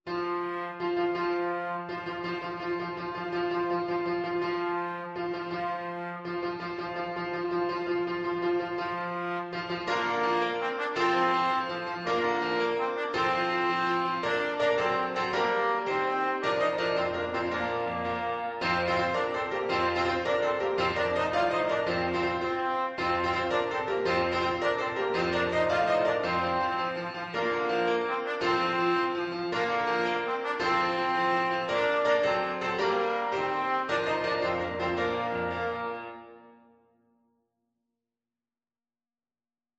Trombone
With energy .=c.110
Bb major (Sounding Pitch) (View more Bb major Music for Trombone )
6/8 (View more 6/8 Music)
F4-F5
Classical (View more Classical Trombone Music)